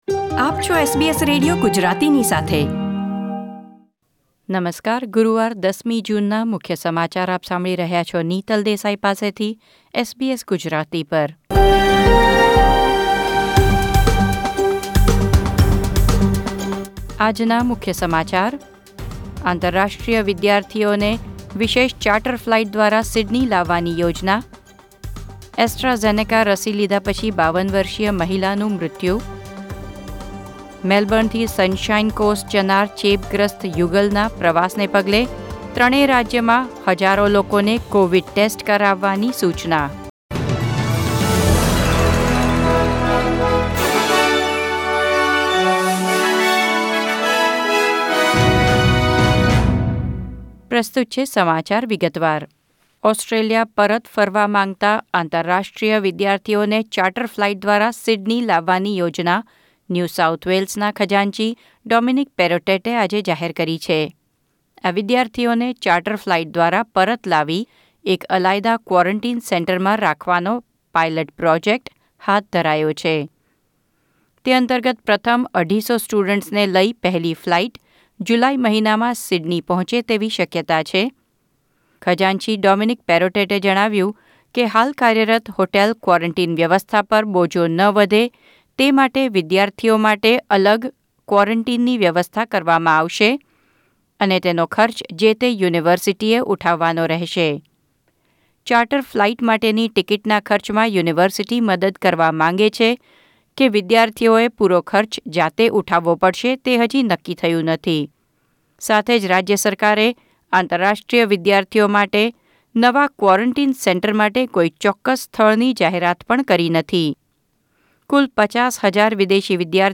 SBS Gujarati News Bulletin 10 June 2021